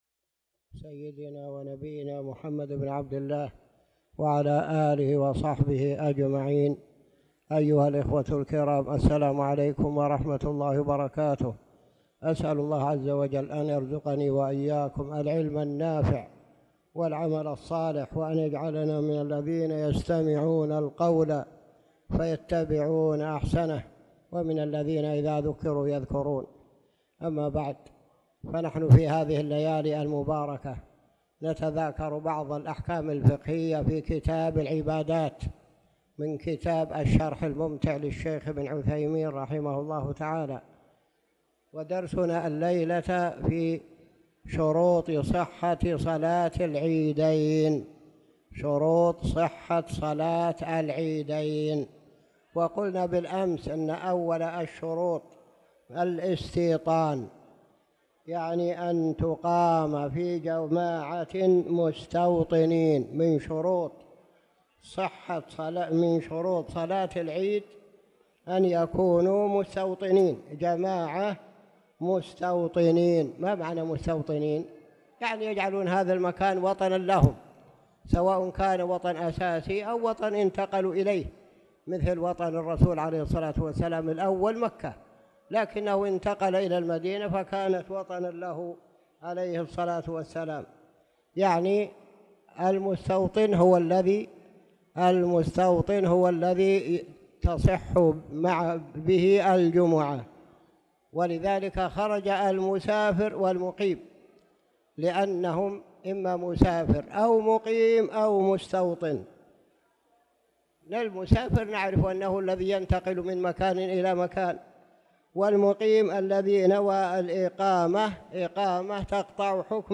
تاريخ النشر ٢٢ شعبان ١٤٣٧ هـ المكان: المسجد الحرام الشيخ